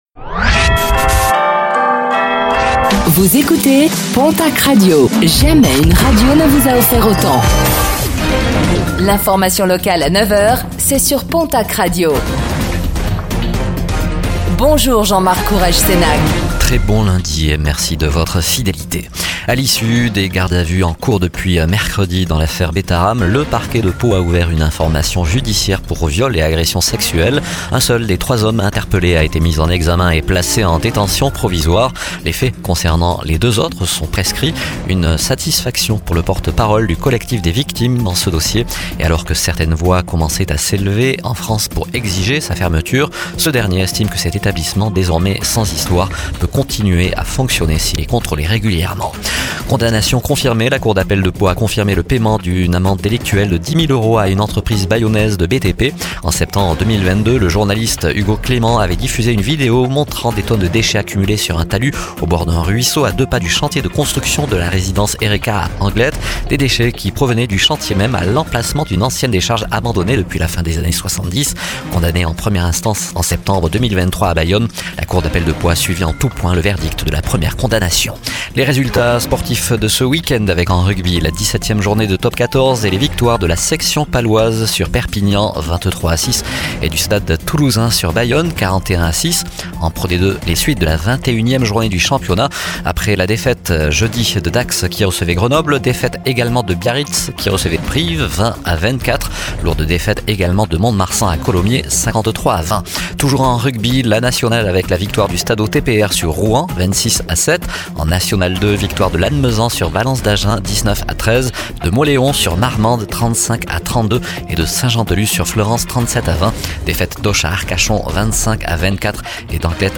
Infos | Lundi 24 février 2025